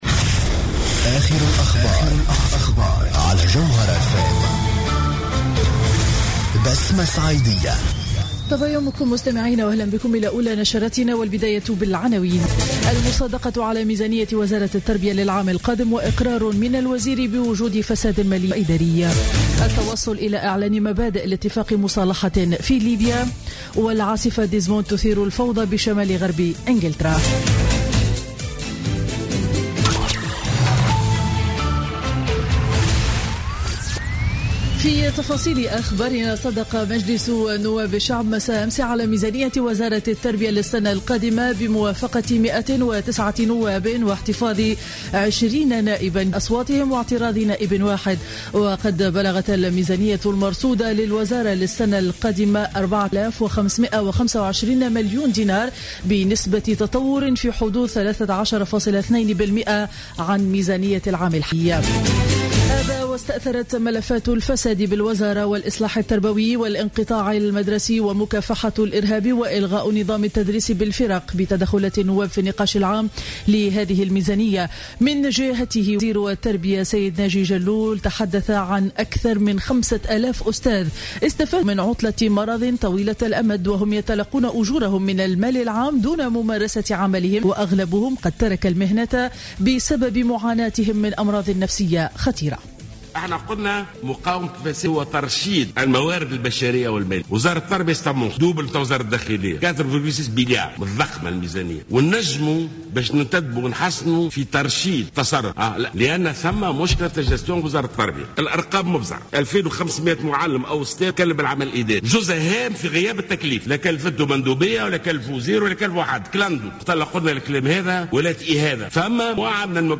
نشرة أخبار السابعة صباحا ليوم الأحد 6 ديسمبر 2015